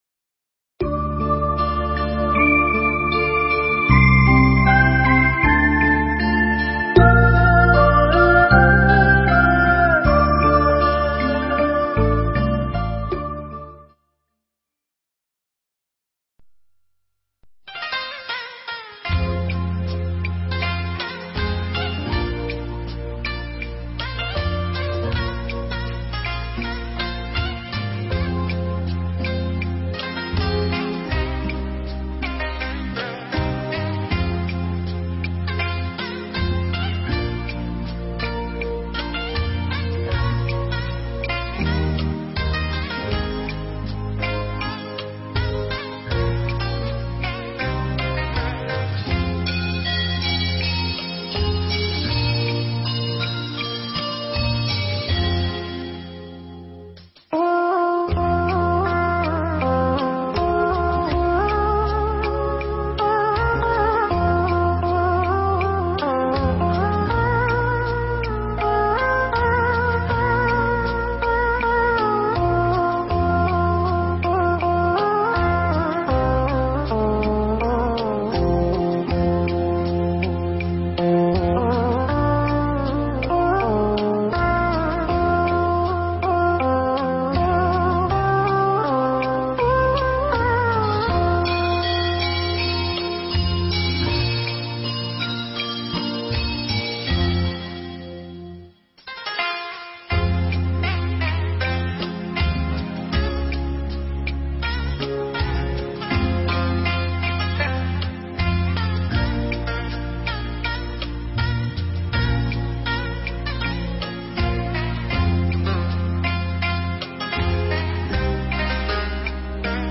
Nghe Mp3 thuyết pháp Tiến Trình An Cư – Tự Tứ – Vu Lan
Mp3 pháp thoại Tiến Trình An Cư